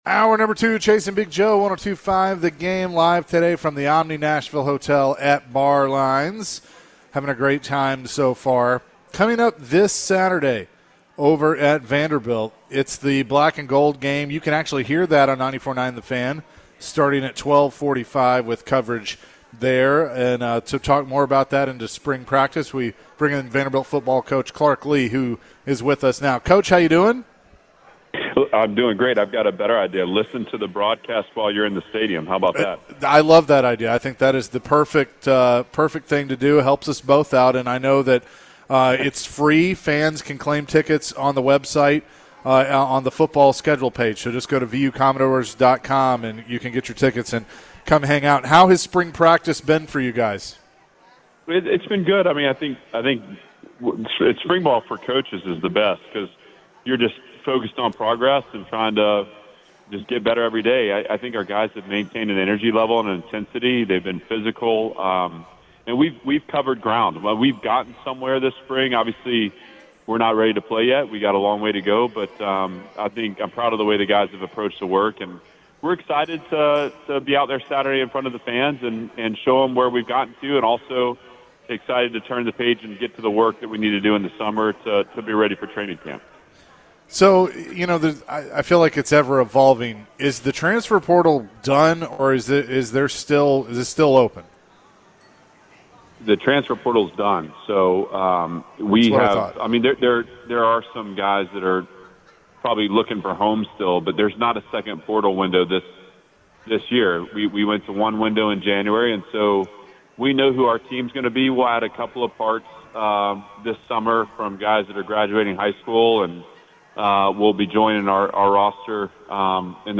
Vanderbilt Football HC Clark Lea joined the show ahead of the spring game.